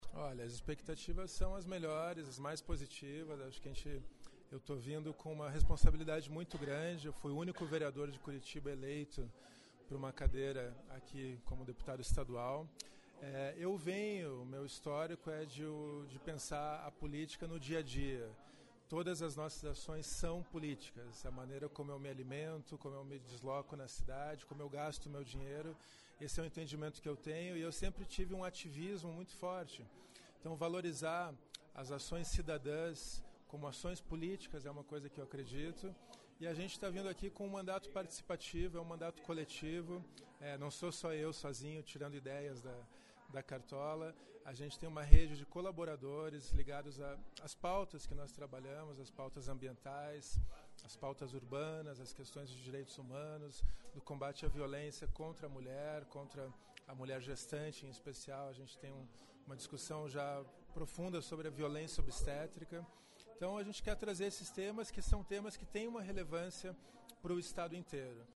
Dando contibuidade à série de entrevistas com os parlamentares de primeiro mandato, o nosso entrevistado desta vez é Goura (PDT).